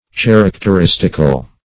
Characteristical \Char`ac*ter*is"tic*al\, a.